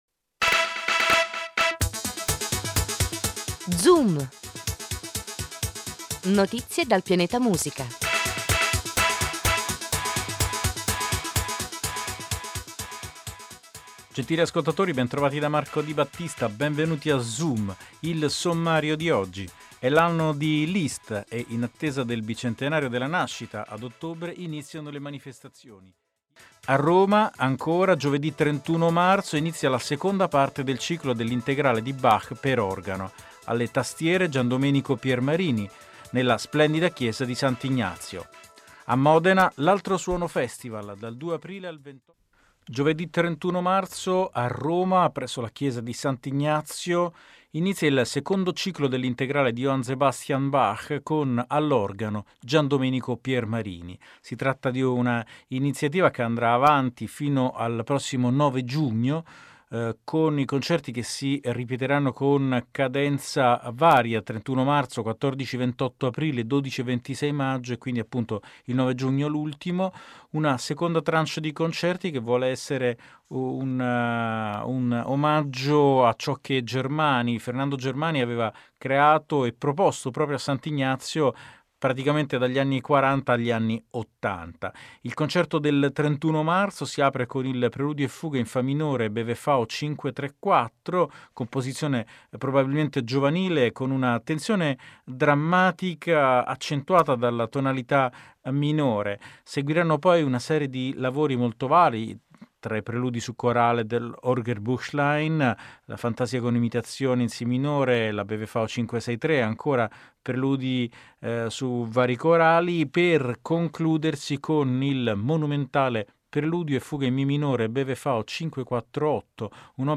Interviste radiofoniche